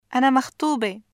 [ʔana maxTuube]